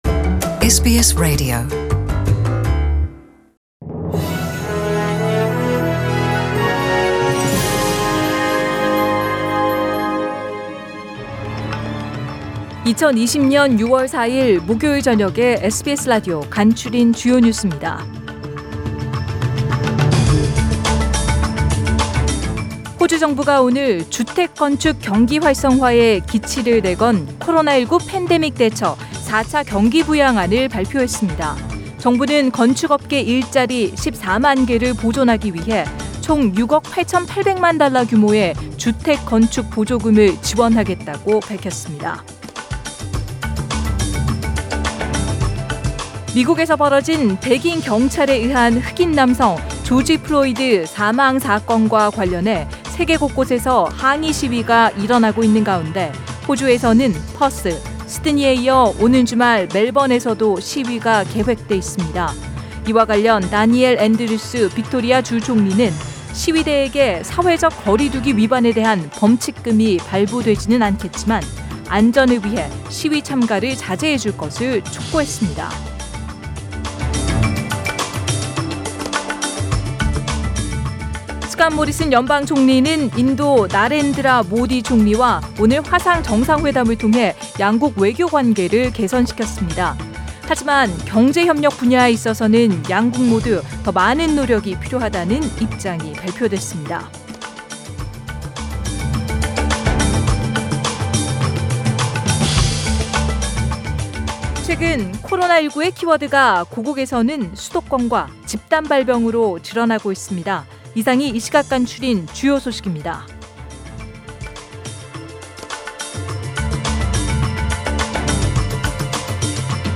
SBS 한국어 뉴스 간추린 주요 소식 – 6월 4일 목요일